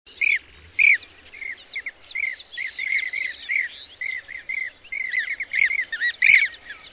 głosy innych kraskowych